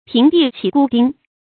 平地起孤丁 píng dì qǐ gū dīng 成语解释 比喻无事生非。